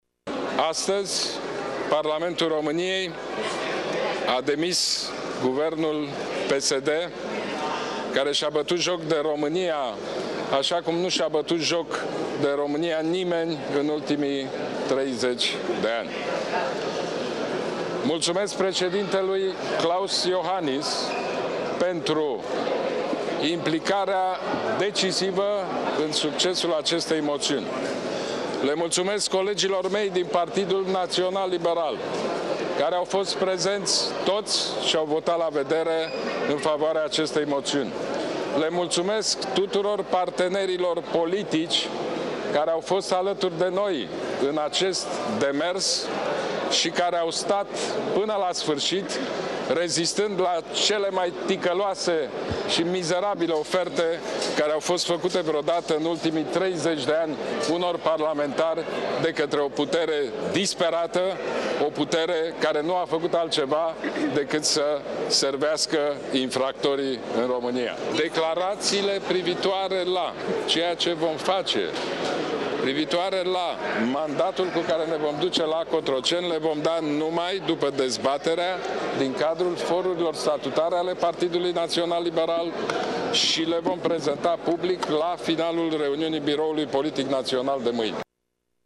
Mâine dimineață, la ora 9, liberalii au convocat Biroul Permanent Național al PNL pentru a stabili strategia pe perioada următoare, a anunțat președintele Ludovic Orban: